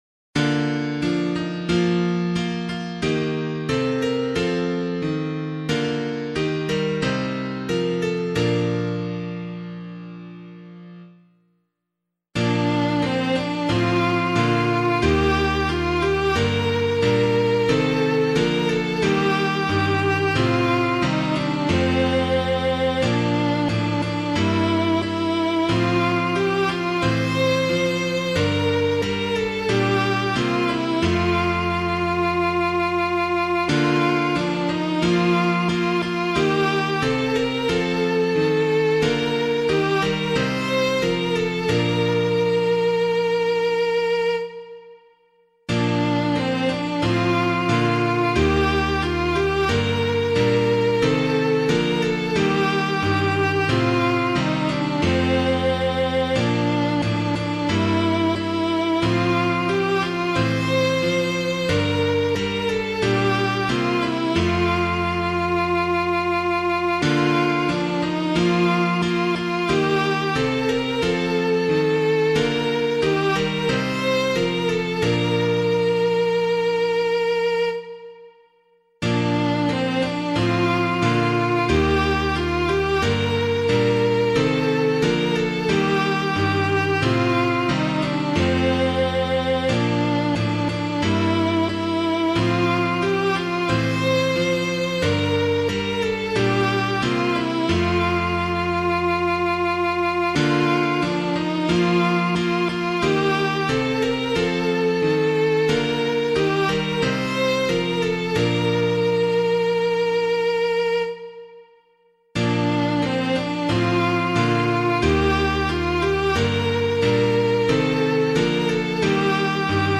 piano
Love Is His Word Love Is His Way [Connaughton - CRESSWELL] - piano.mp3